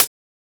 edm-hihat-55.wav